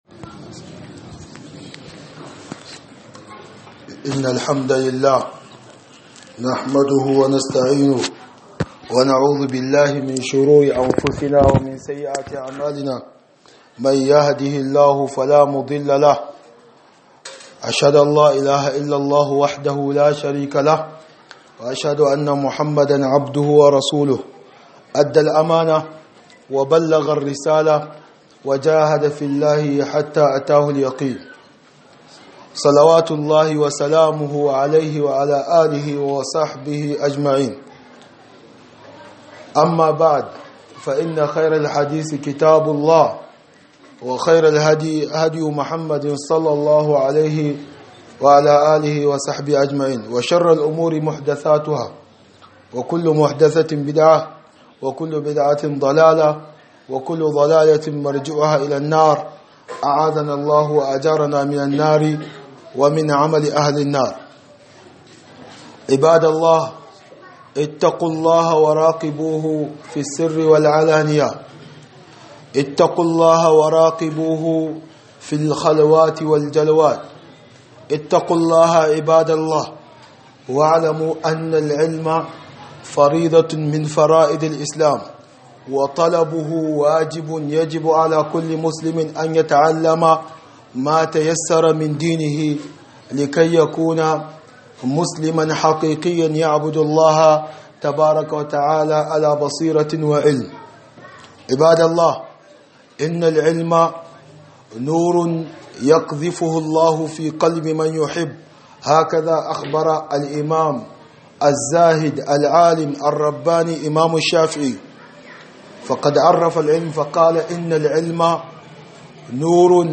خطبة بعنوان من فضائل طلب العلم الشرعي